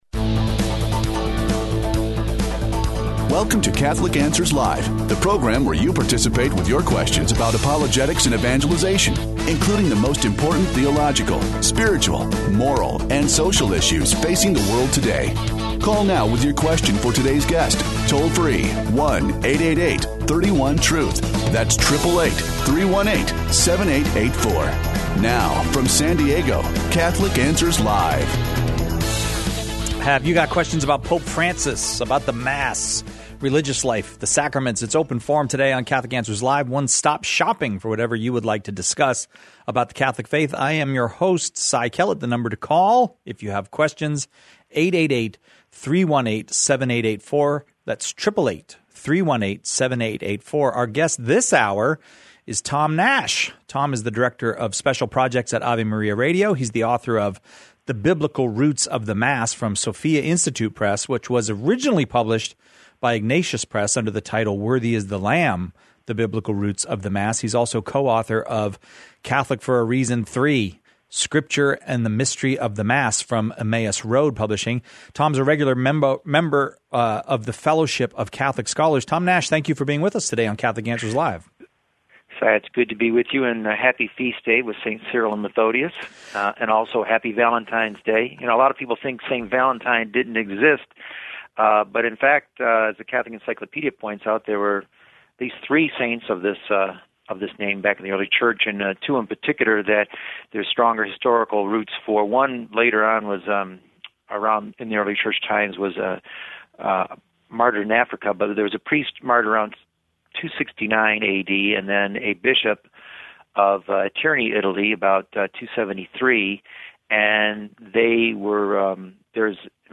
The callers choose the topics during Open Forum, peppering our guests questions on every aspect of Catholic life and faith, the moral life, and even philosophic...